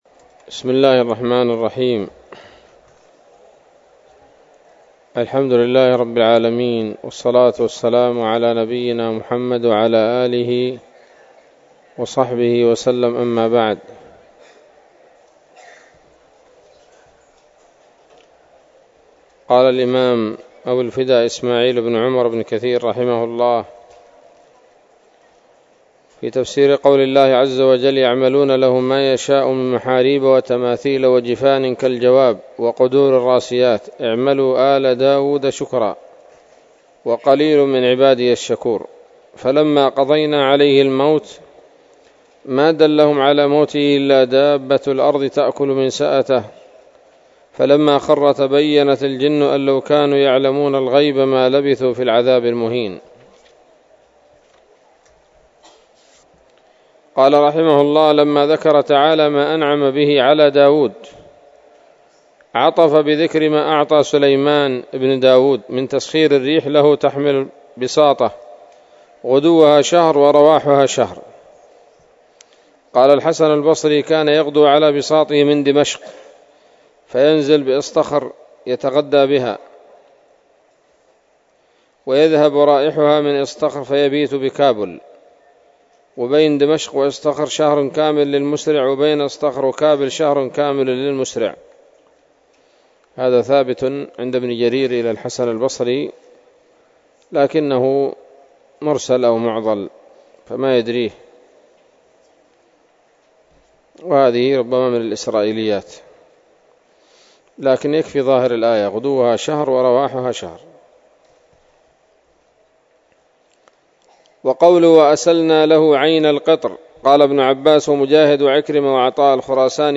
الدرس الرابع من سورة سبأ من تفسير ابن كثير رحمه الله تعالى